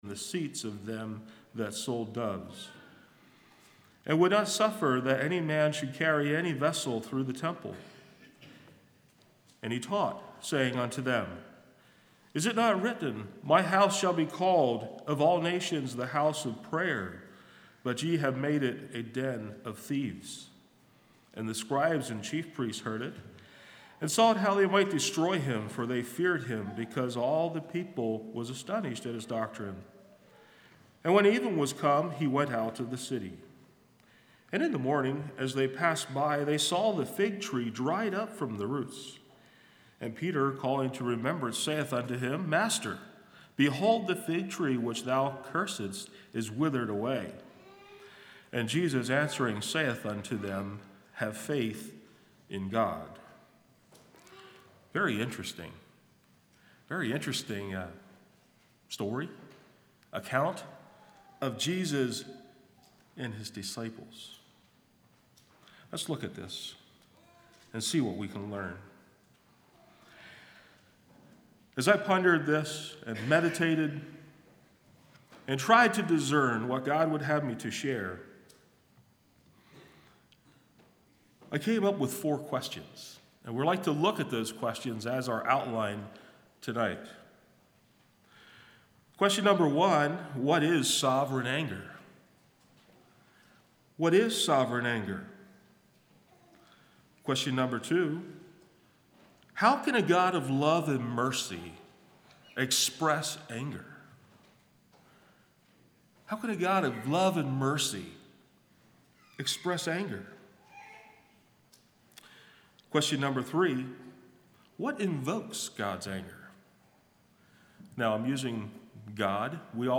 Sermons 05.08.22 Play Now Download to Device Sovereign Anger Congregation